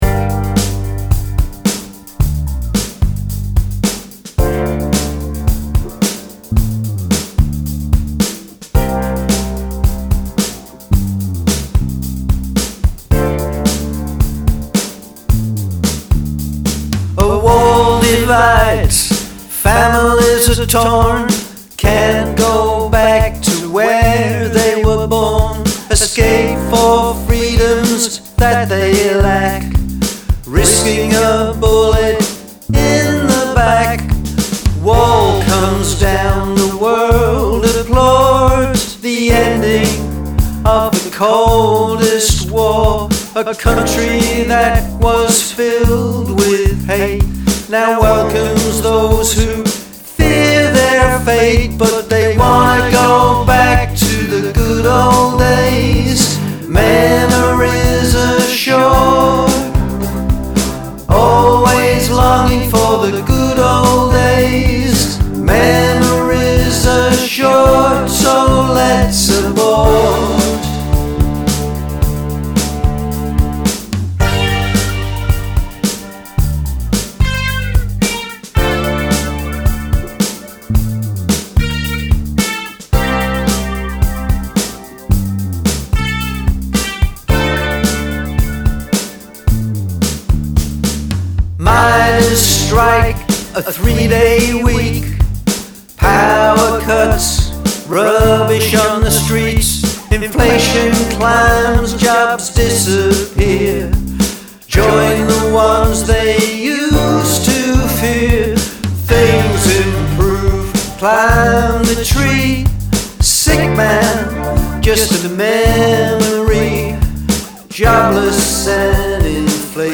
Musique et chansons